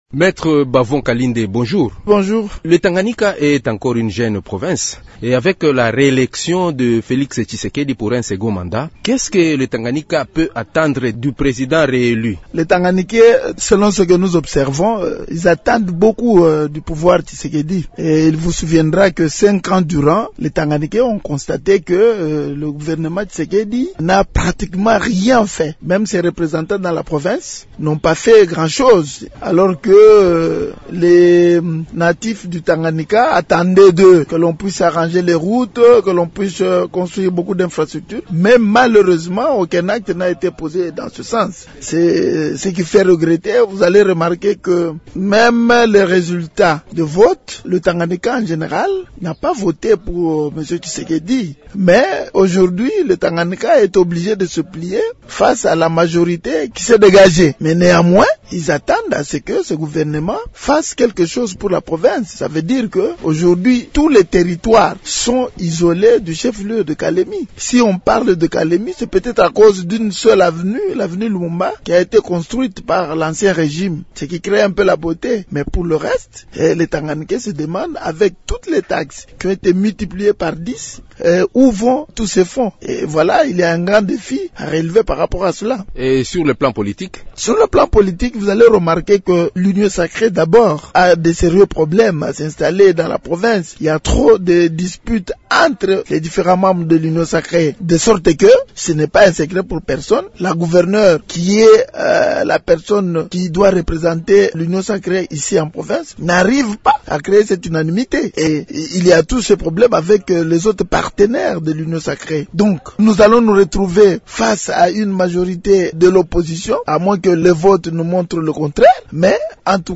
L'invité du jour, Émissions / enfant-soldat, recrutement, Monusco